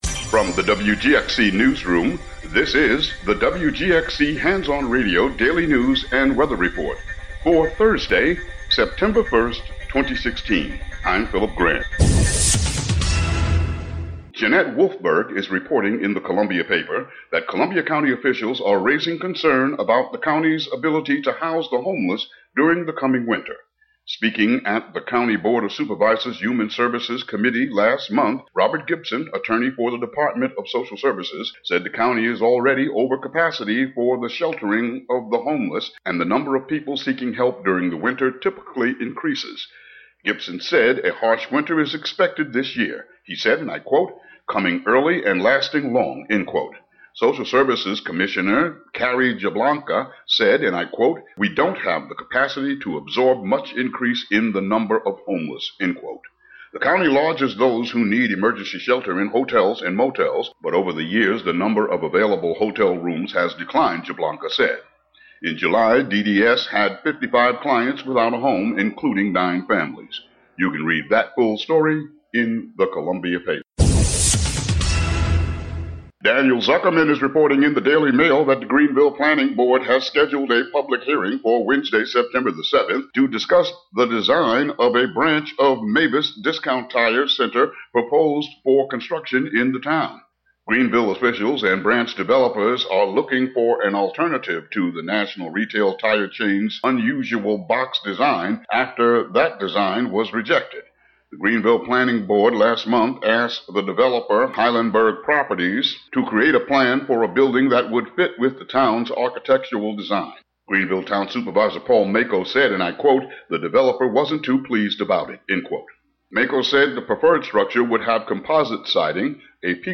Uncategorized Local headlines and weather